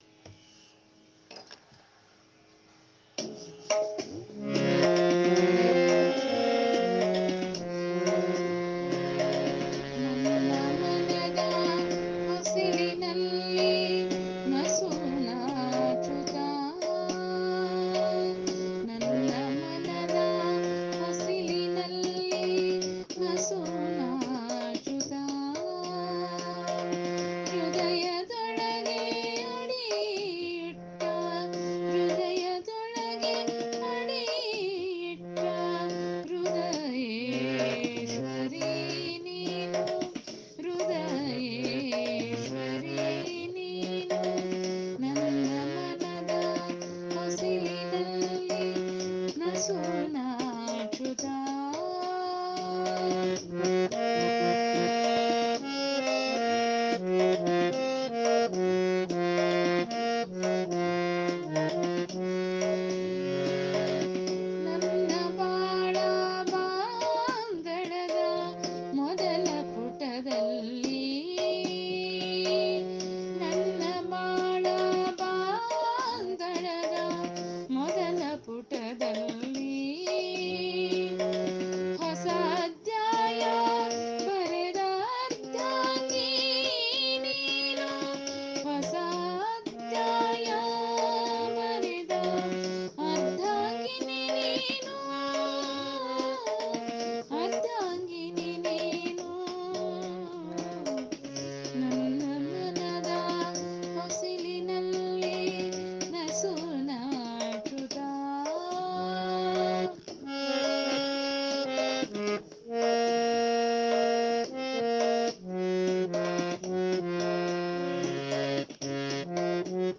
ಹೂವಿನ ಹಡಗಲಿ ಕಾವ್ಯ ಪ್ರಕಾರ: ಗಜಲ್
ಗಝಲ್
🖕ರಾಗಸಂಯೋಜನೆ-ಗಾಯನ